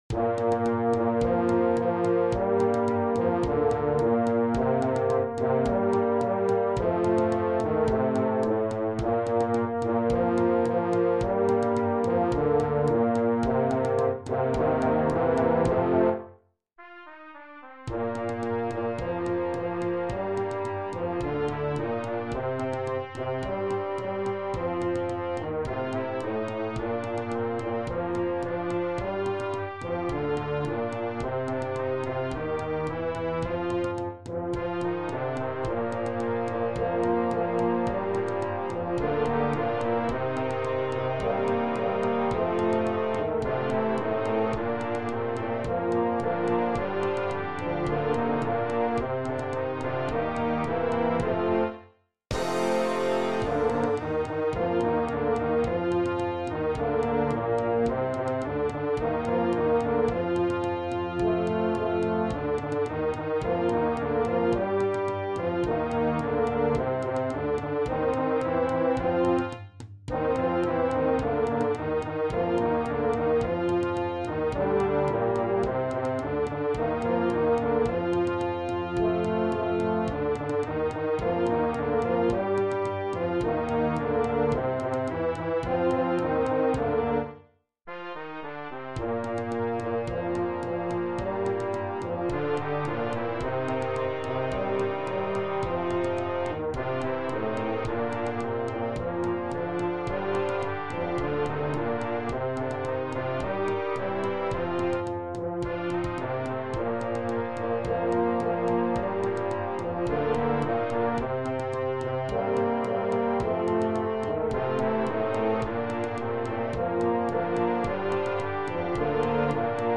Young Concert Band $55.00